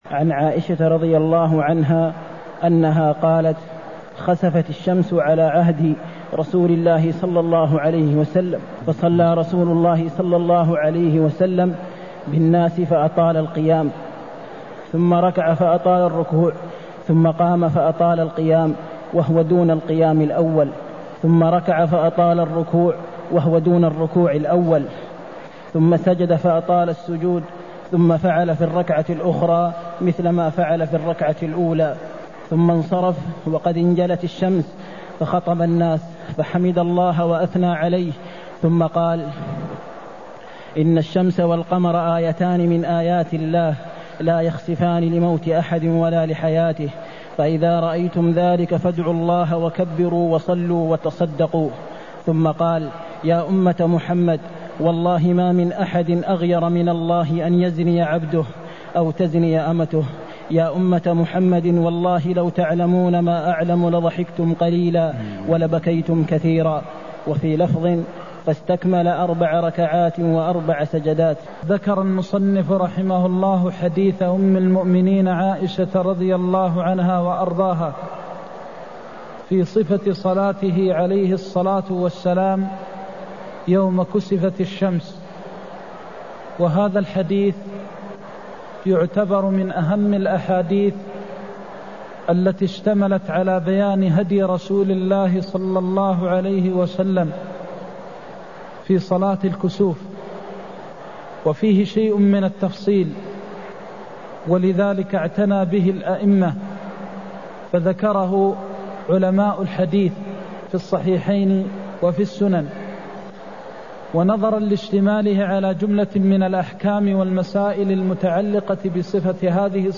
المكان: المسجد النبوي الشيخ: فضيلة الشيخ د. محمد بن محمد المختار فضيلة الشيخ د. محمد بن محمد المختار صفة صلاة الكسوف (143)